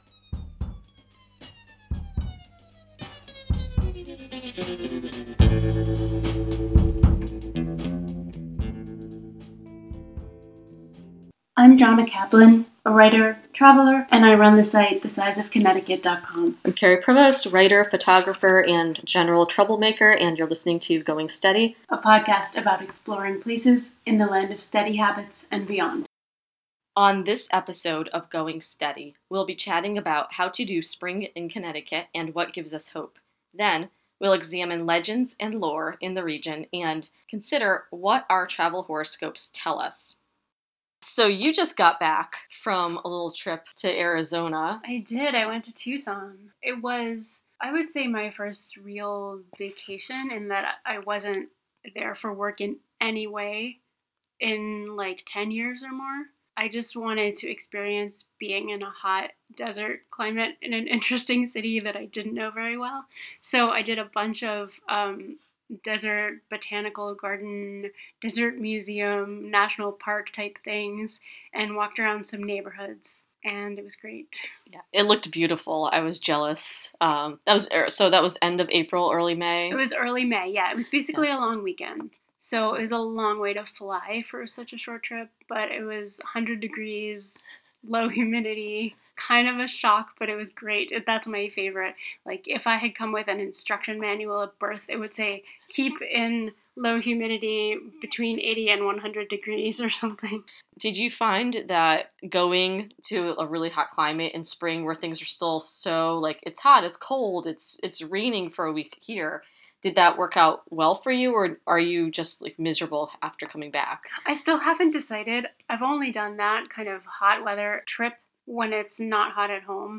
Note : This podcast contains occasional, relatively lightweight curse-words.